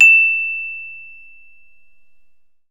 CELESTE E5.wav